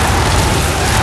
tyres_grass_skid.wav